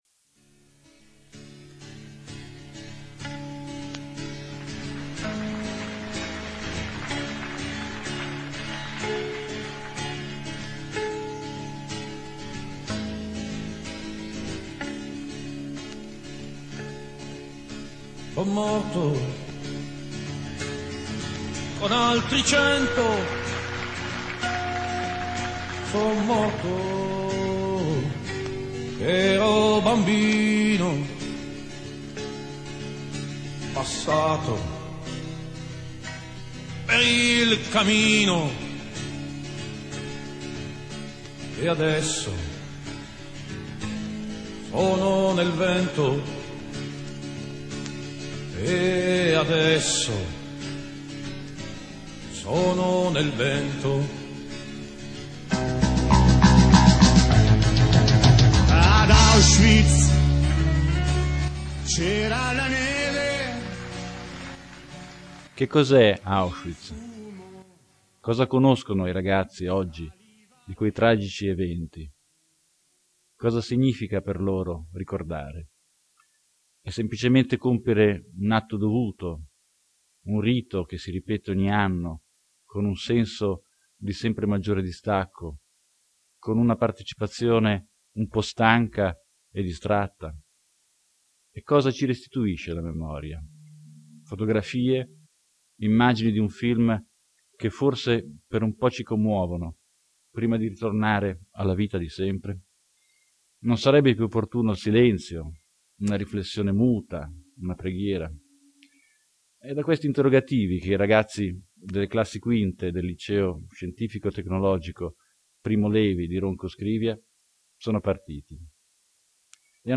Letture e intervista sulla SHOAH